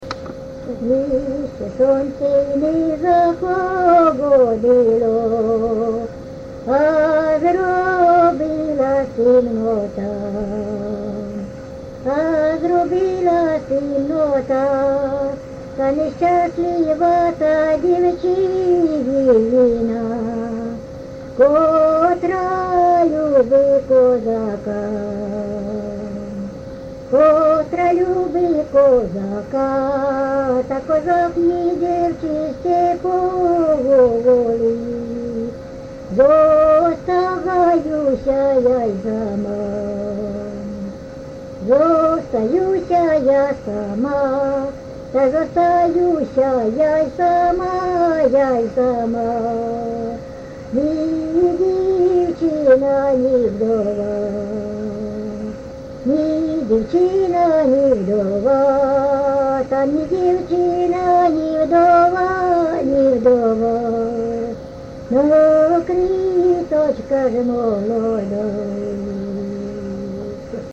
ЖанрПісні з особистого та родинного життя, Козацькі
Місце записус. Ярмолинці, Роменський район, Сумська обл., Україна, Слобожанщина